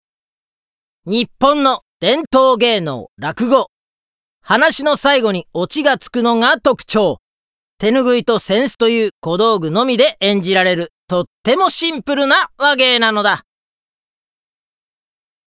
ボイスサンプル ＜ナレーション＞
6_ナレーション.mp3